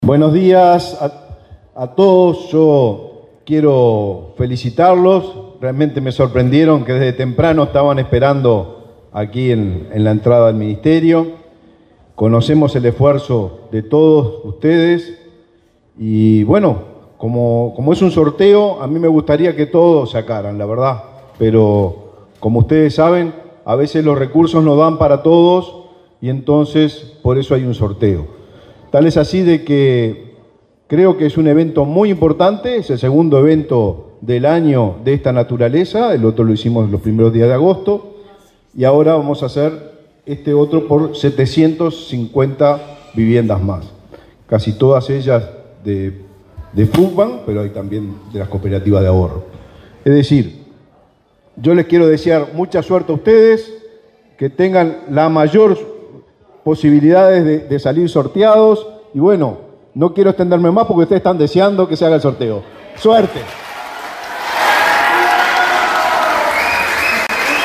Palabras del ministro de Vivienda, Raúl Lozano
El titular de la cartera, Raúl Lozano, participó en el acto y elogió el esfuerzo de los participantes.